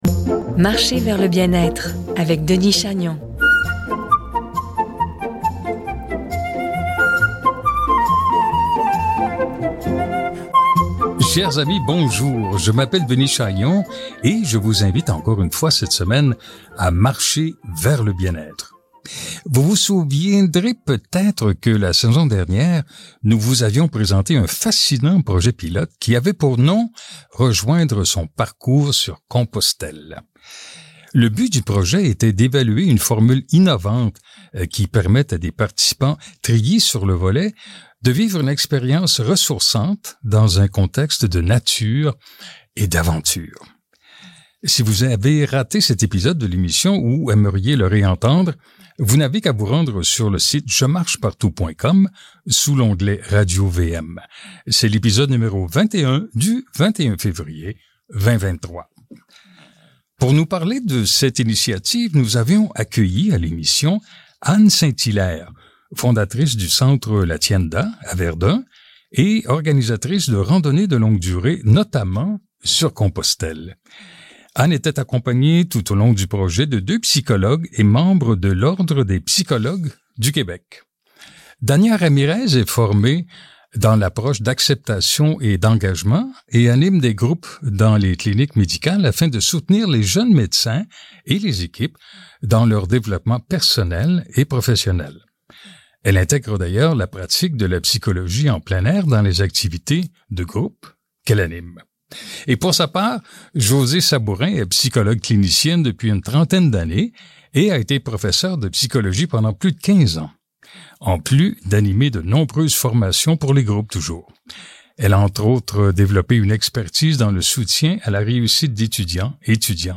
Le projet a eu lieu, le chemin a été complété et, au cours du présent épisode, nous nous entretenons avec les trois mêmes invitées à propos du déroulement et des apprentissages réalisés à partir de cette formule innovante.